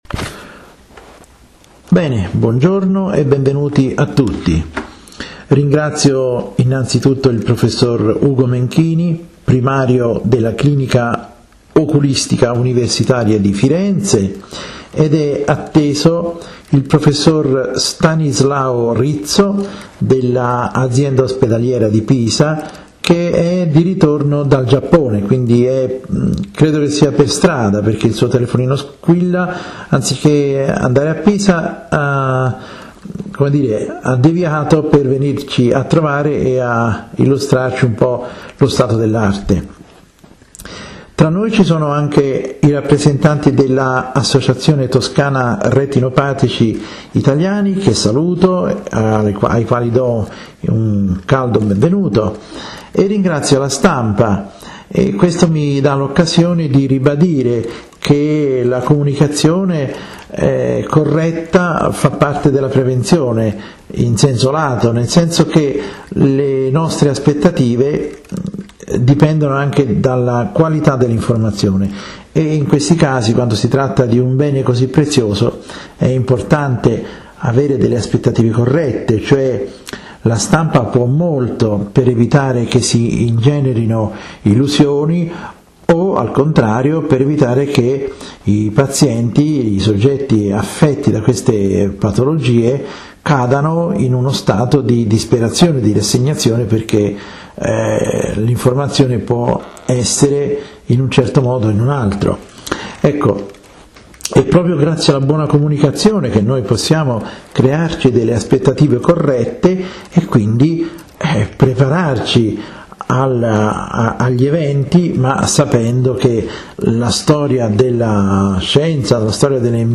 Ascolta la conferenza stampa sull'impianto della retina artificiale (durata: 66 min)